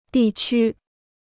地区 (dì qū) Free Chinese Dictionary